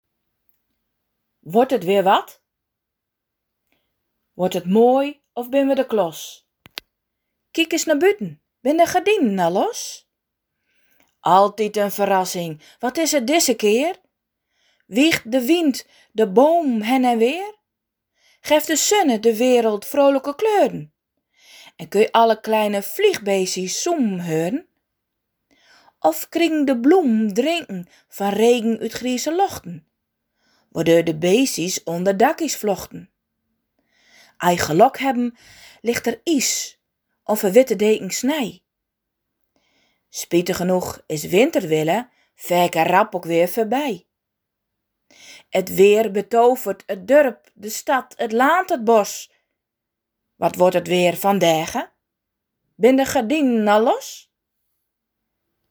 De tekst op de posters is ook ingesproken!
Gedicht-Wodt-et-weer-wat.mp3